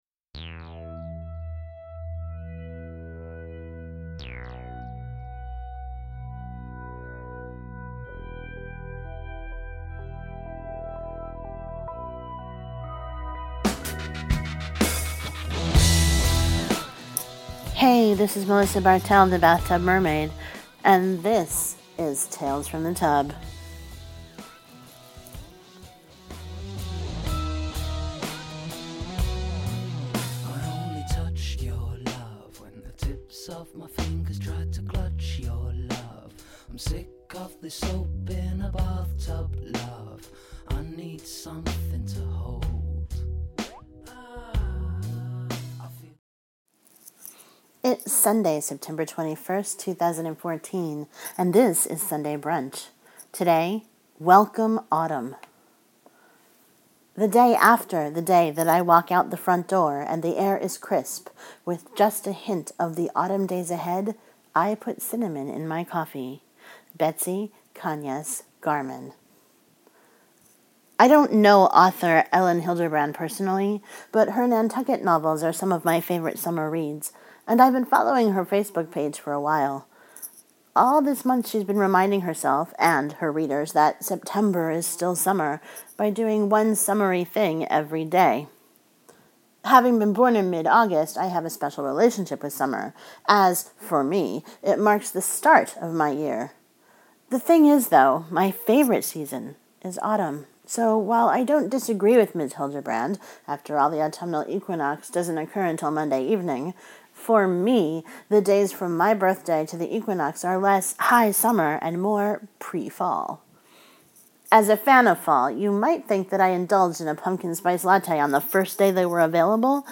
Music for The Bathtub Mermaid is provided by Mevio’s Music Alley, a great resource for podsafe music.